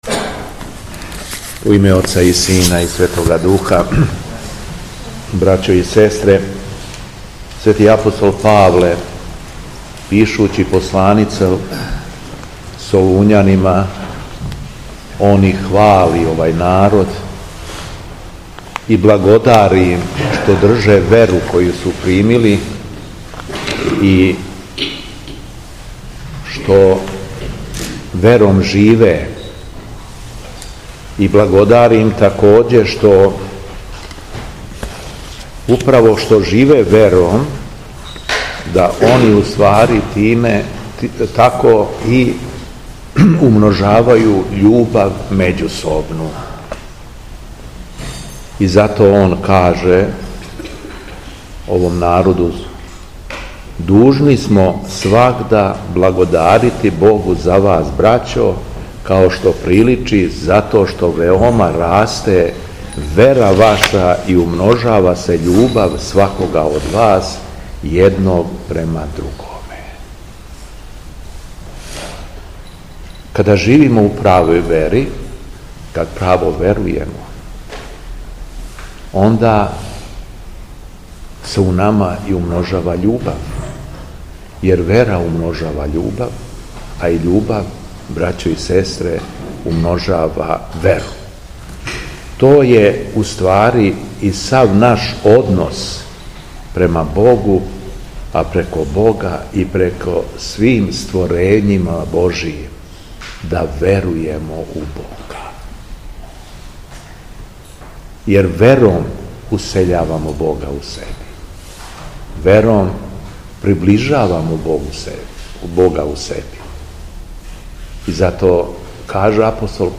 У четвртак 28. децембра 2023. године, Епископ шумадијски Г. Јован служио је свету Литургију у Старој Милошевој Цркви у Крагујевцу уз саслужење братств...
Беседа Његовог Преосвештенства Епископа шумадијског г. Јована